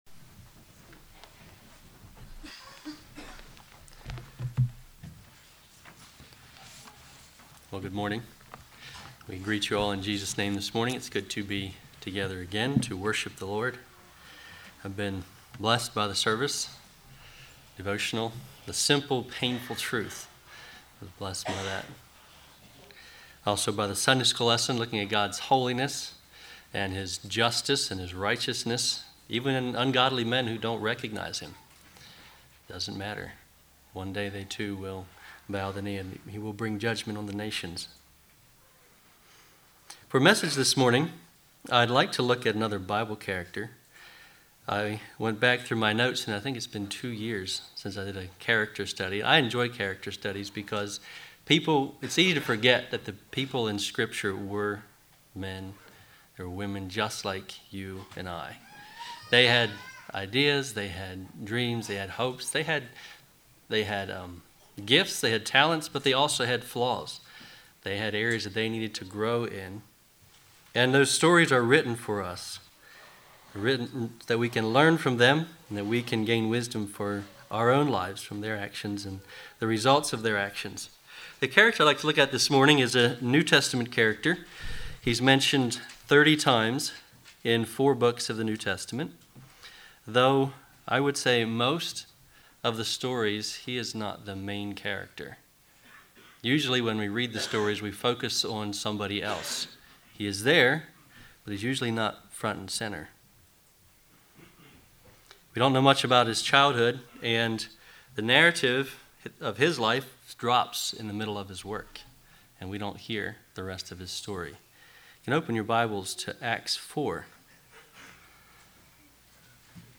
Congregation: Winchester